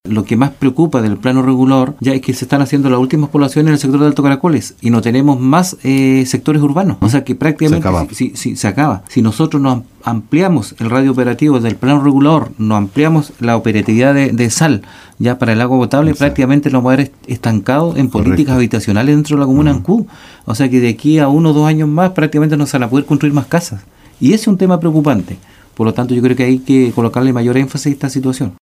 Destacó el concejal de Ancud que la situación de no aprobación del nuevo Plano Regulador puede impactar en áreas tan sensibles como la habitacional, haciendo que no se cuente con más zonas para la construcción de viviendas sociales.